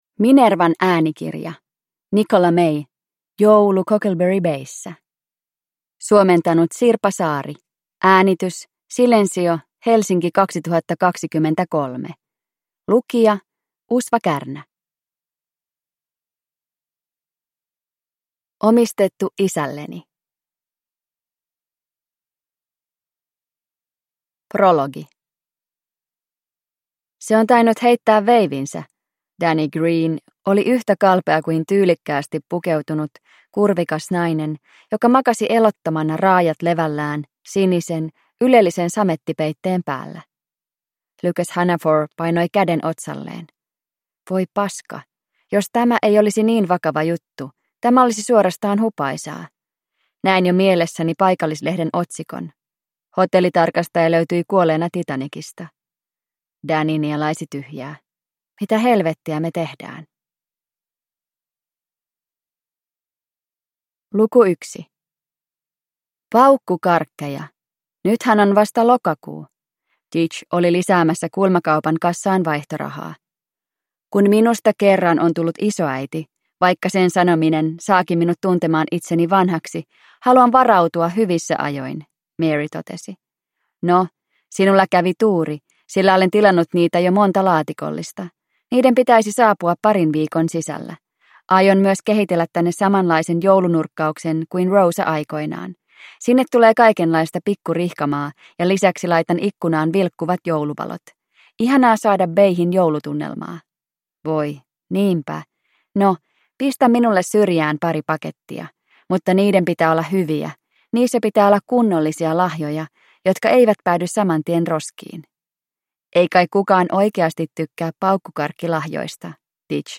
Joulu Cockleberry Bayssa – Ljudbok – Laddas ner